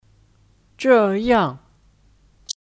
这样 Zhèyàng (Kata ganti): Begini